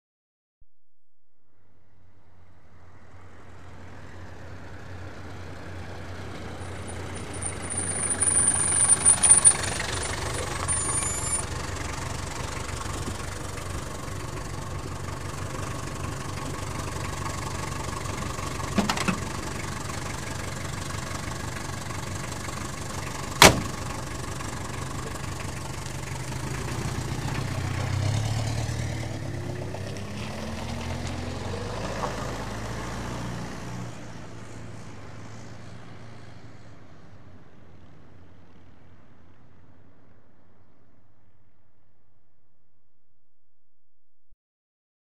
Звуки автомобилей
Авто подъезжает, открывают дверь, закрывают, уезжает